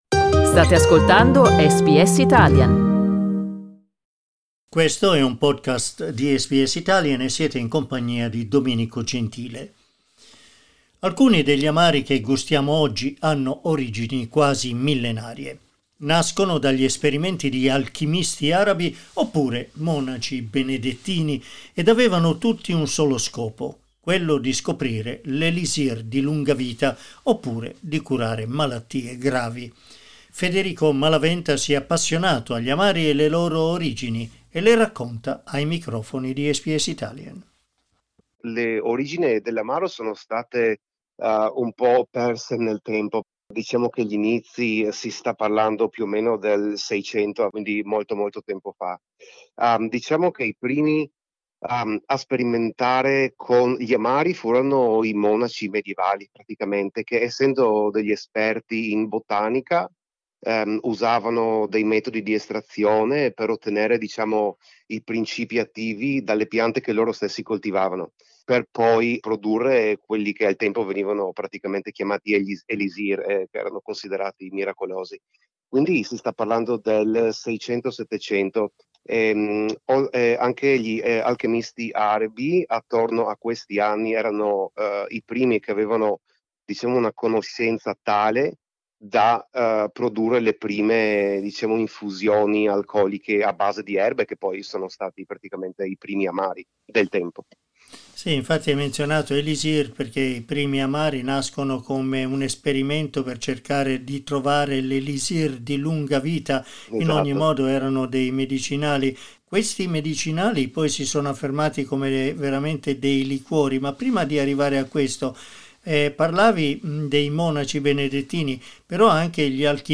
Ascolta l'intervista: LISTEN TO La storia degli amari: dagli alchimisti arabi ai monaci benedettini SBS Italian 10:47 Italian Le persone in Australia devono stare ad almeno 1,5 metri di distanza dagli altri.